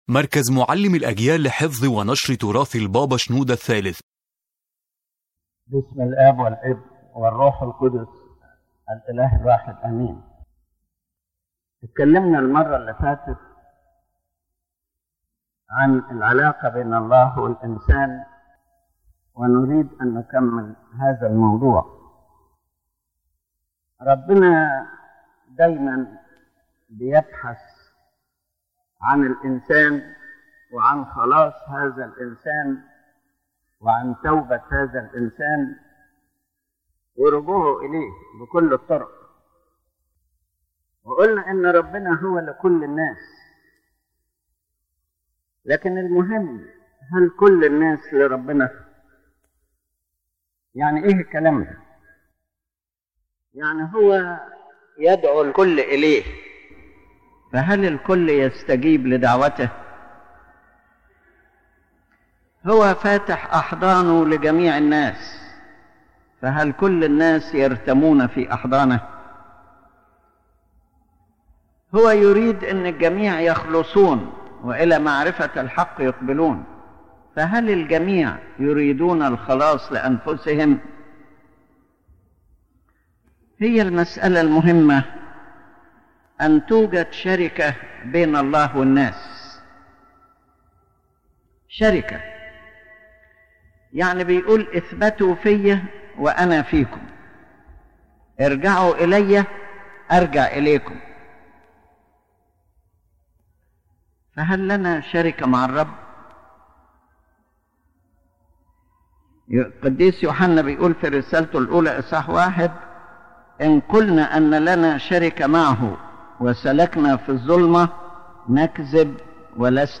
The lecture revolves around the true nature of the relationship between God and man, explaining that God loves everyone and calls all to salvation, but not all souls respond to this divine call or remain steadfast in it.